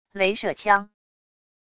雷射枪wav音频